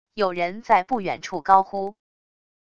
有人在不远处高呼wav音频